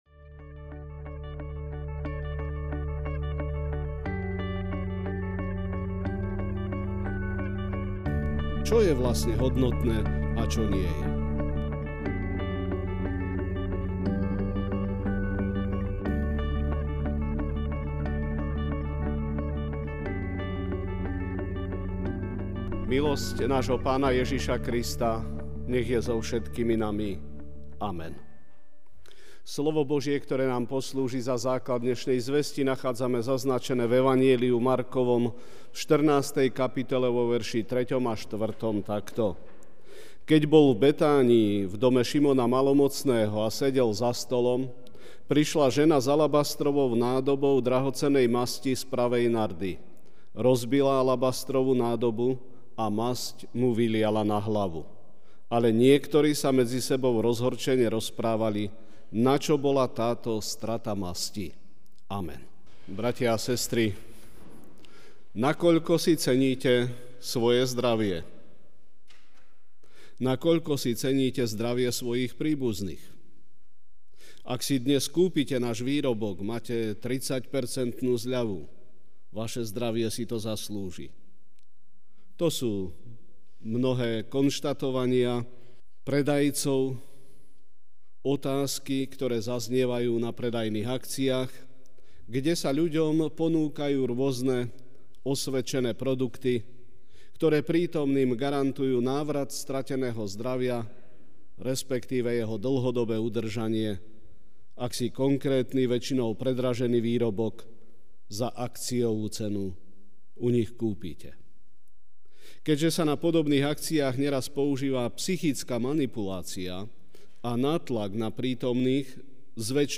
Večerná kázeň: Akú hodnotu má pre teba Pán Ježiš?